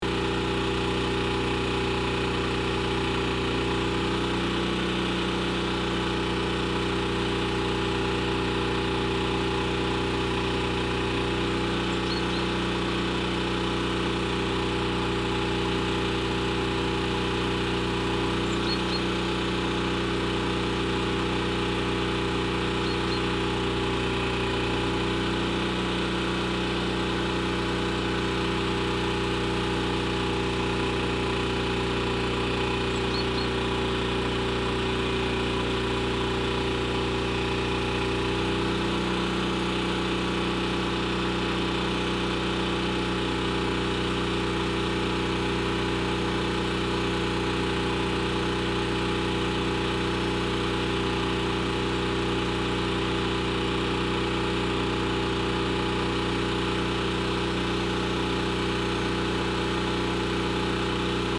Download Generator sound effect for free.
Generator